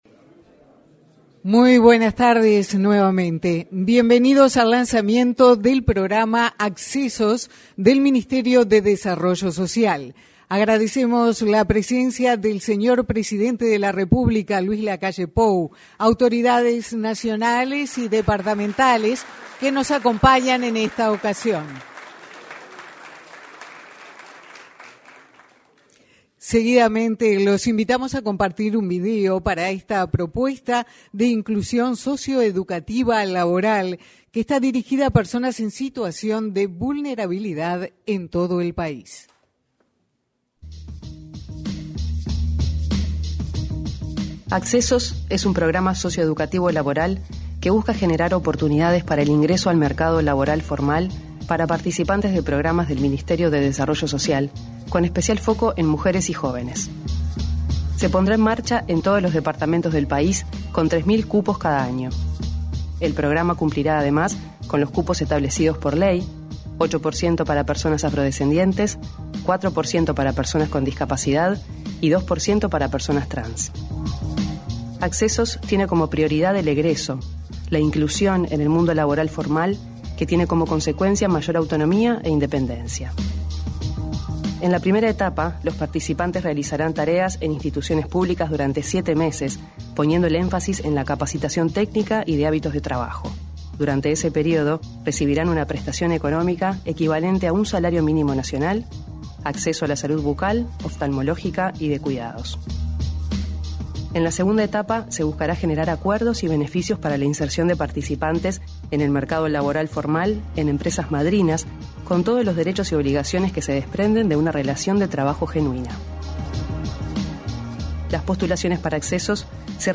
Lanzamiento del programa Accesos 12/05/2022 Compartir Facebook X Copiar enlace WhatsApp LinkedIn En el lanzamiento del programa Accesos, realizado este jueves 12 en el salón de actos de la Torre Ejecutiva, se expresaron el ministro de Desarrollo Social, Martín Lema, y el director nacional de Gestión Territorial de esa cartera, Alejandro Sciarra.